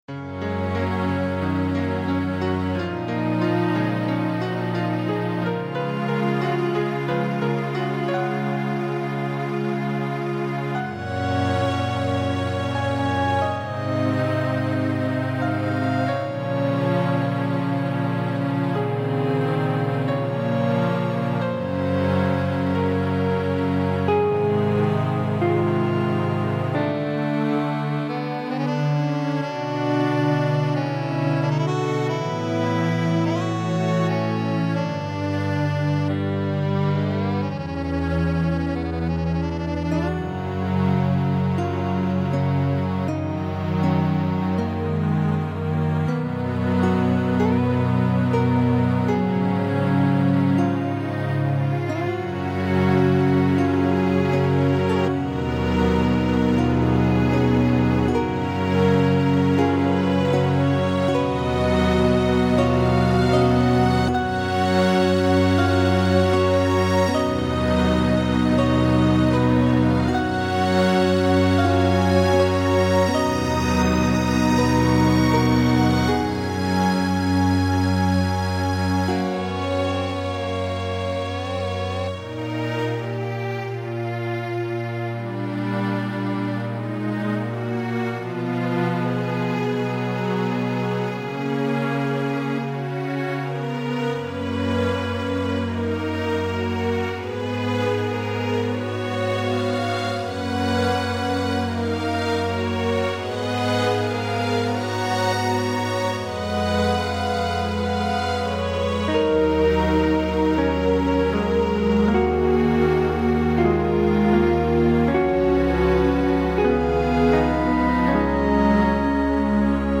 CINEMATIC MUSIC